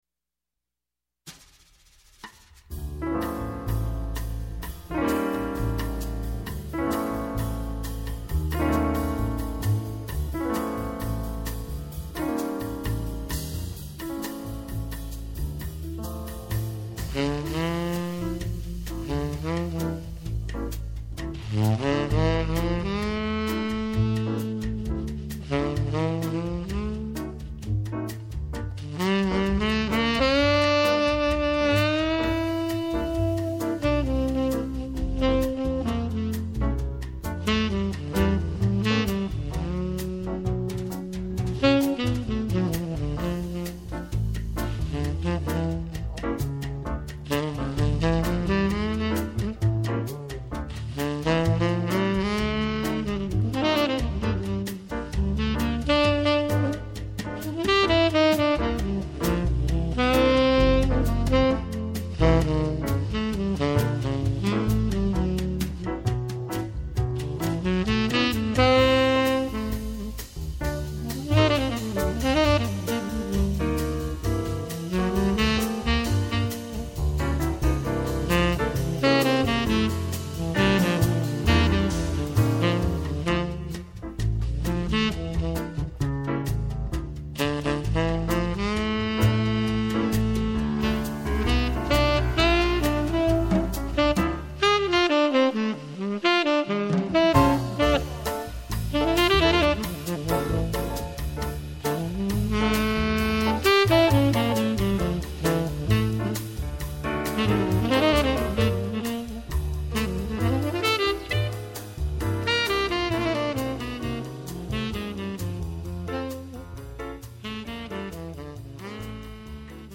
Jazz Quartett mit Saxophone/Jazz Quartet with saxophone